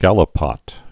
(gălə-pŏt, -pō)